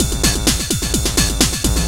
DS 128-BPM B2.wav